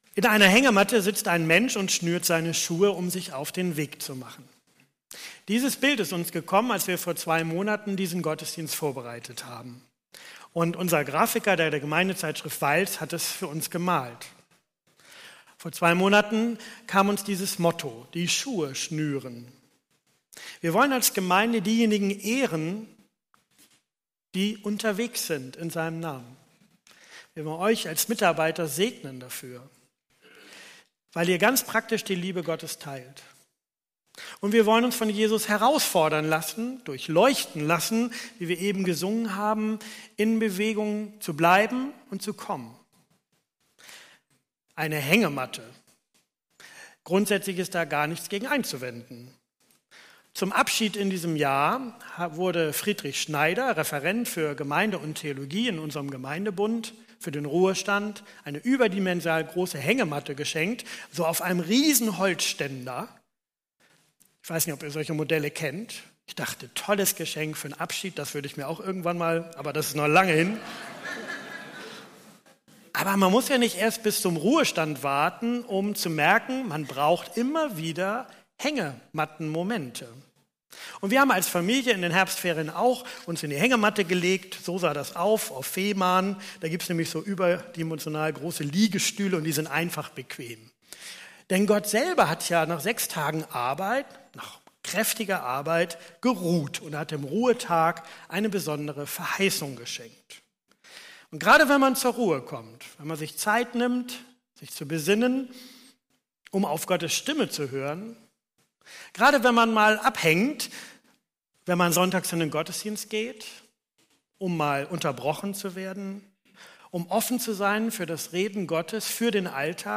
Predigttext: Matthäus 21, 28-32 Spieldauer: 23 Minuten